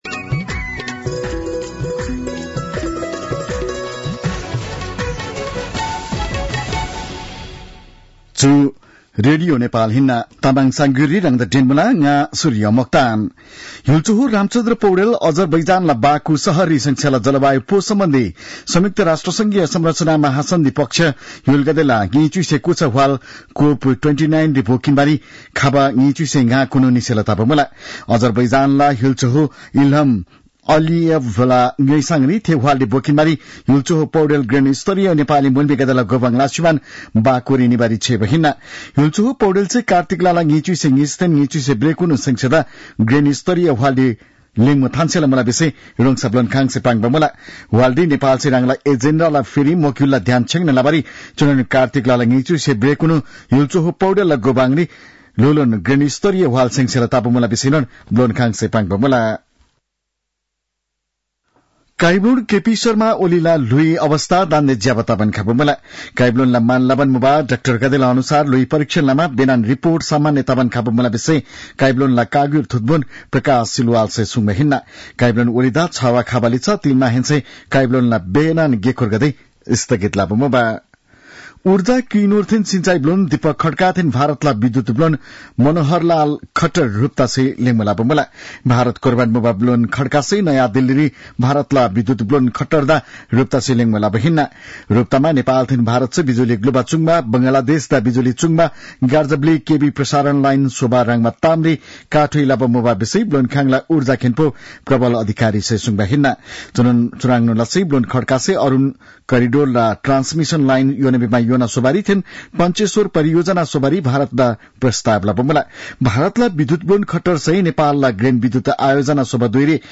तामाङ भाषाको समाचार : २२ कार्तिक , २०८१